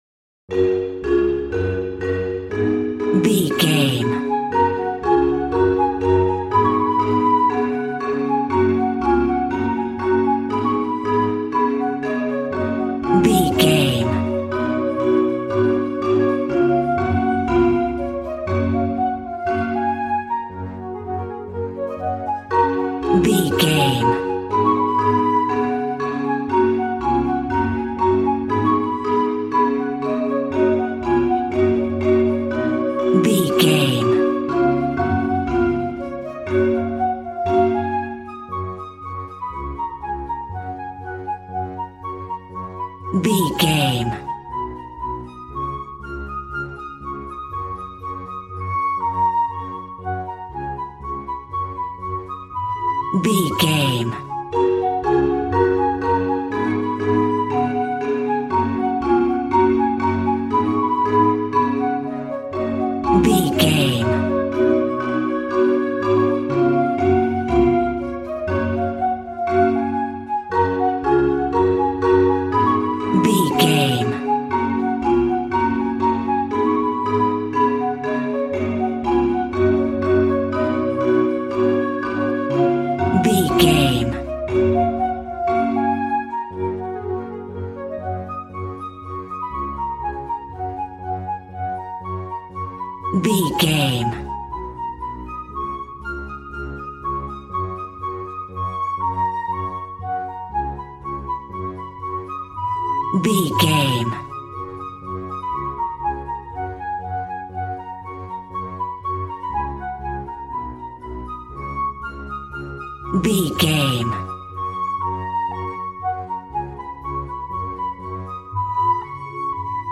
Ionian/Major
G♭
positive
cheerful/happy
joyful
drums
acoustic guitar